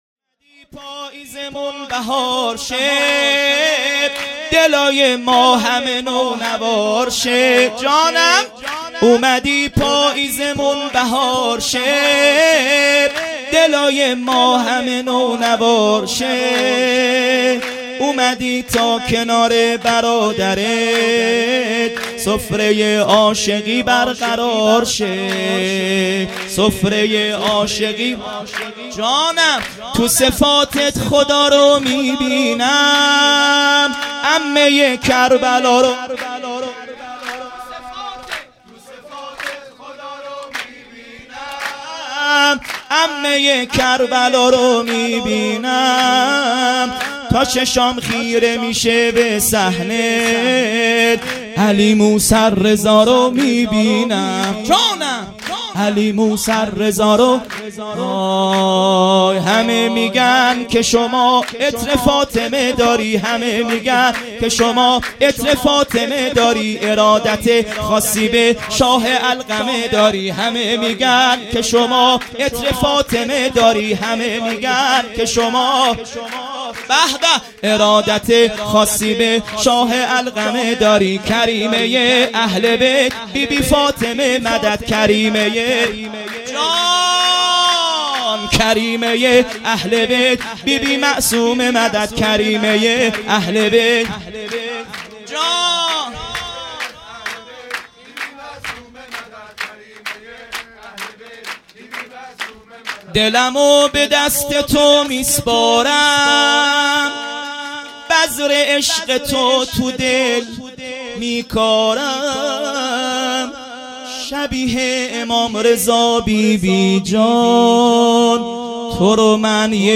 هیئت مکتب الزهرا(س)دارالعباده یزد - سرود ۱ | اومدی پاییزمون بهار شه مداح